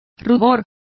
Complete with pronunciation of the translation of glow.